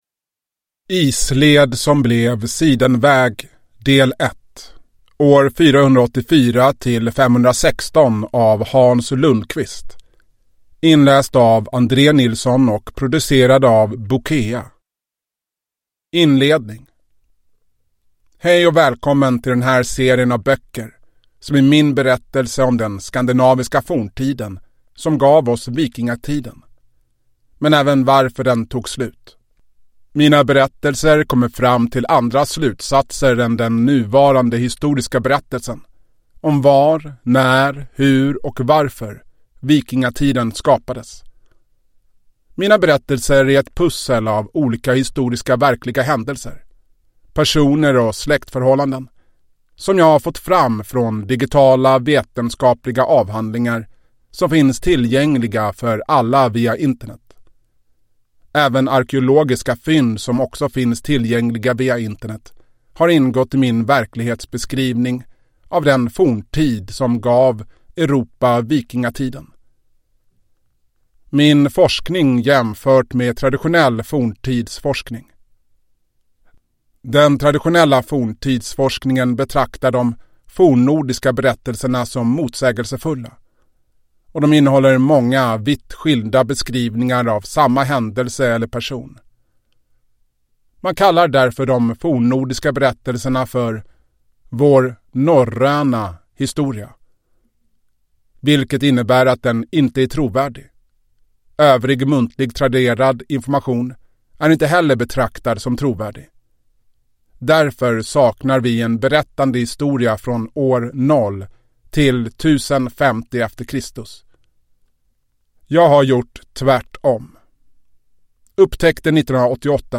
Isled som blev sidenväg. Del 1, År 484-516 – Ljudbok